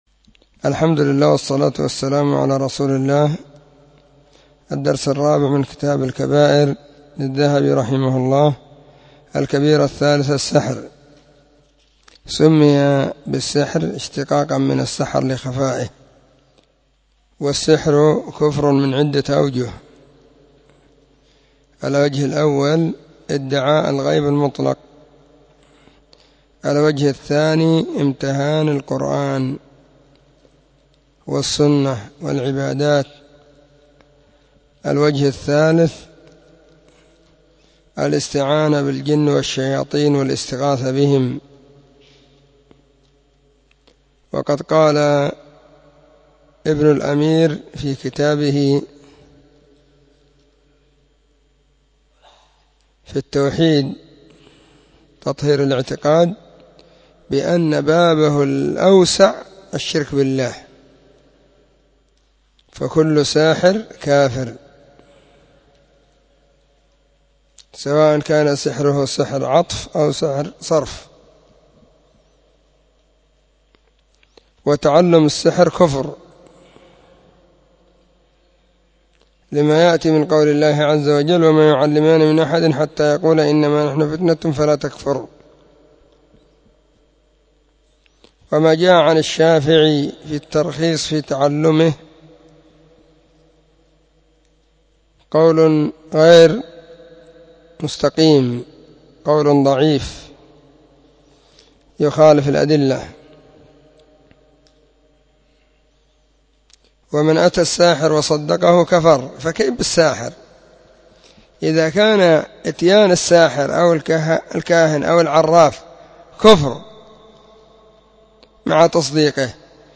🕐 [بين مغرب وعشاء – الدرس الثاني]
كتاب-الكبائر-الدرس-4.mp3